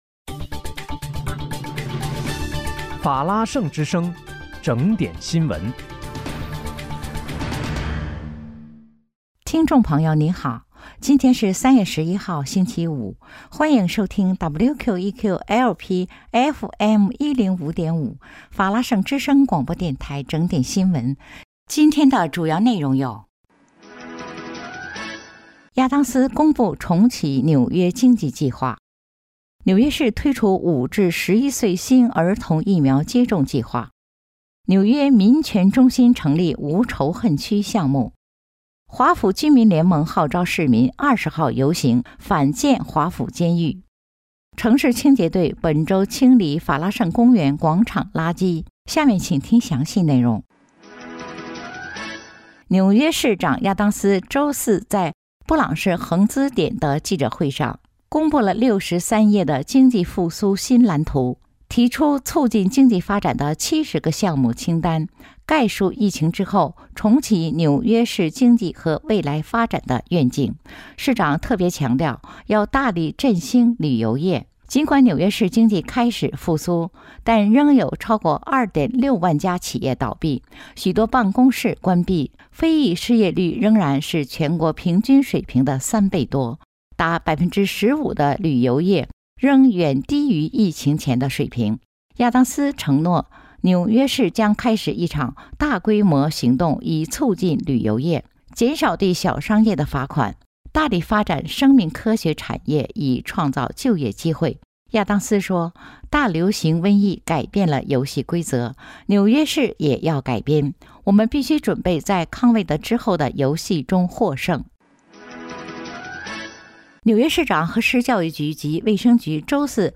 3月11日（星期五）纽约整点新闻
听众朋友您好！今天是3月11号，星期五，欢迎收听WQEQ-LP FM105.5法拉盛之声广播电台整点新闻。